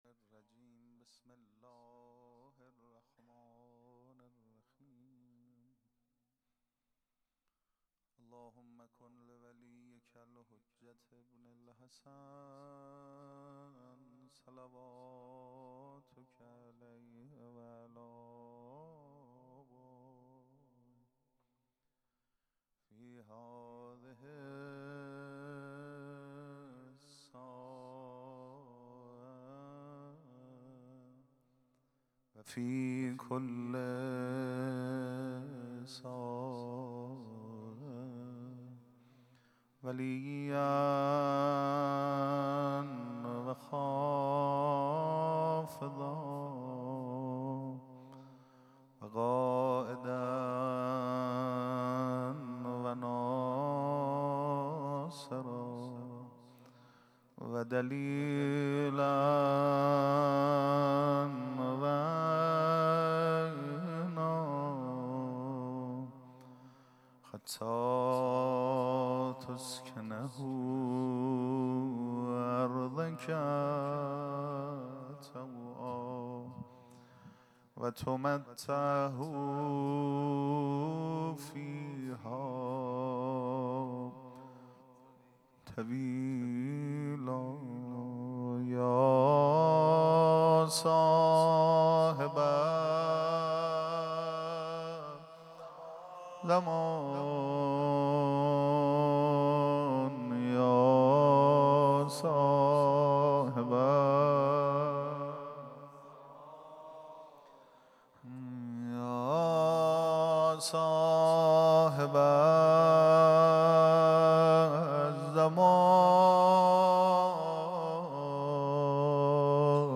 پیش منبر
مداح